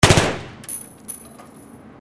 Play, download and share Famas Burst original sound button!!!!
famas-burst-inside.mp3